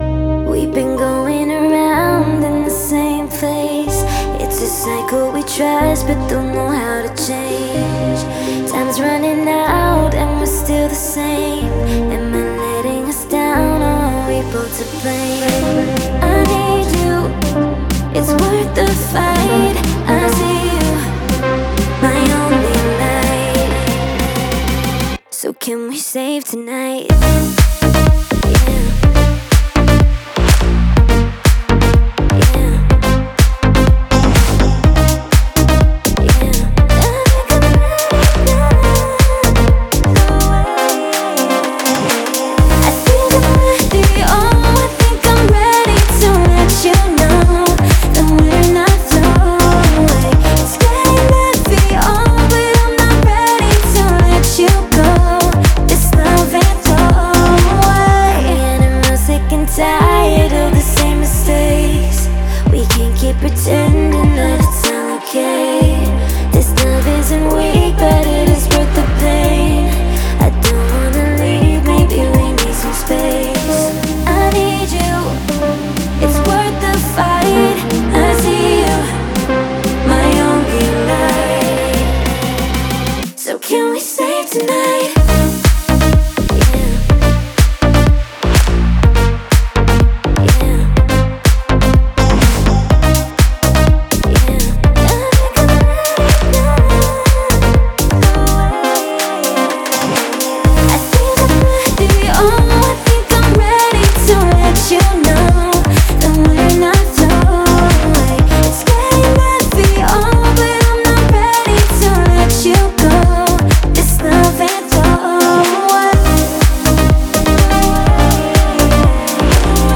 это трек в жанре электро-поп